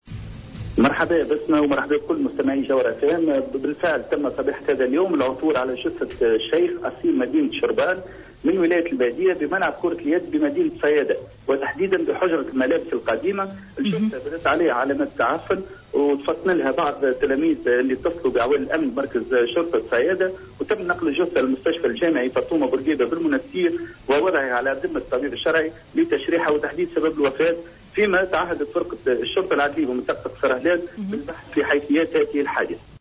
التفاصيل في المراسلة التالية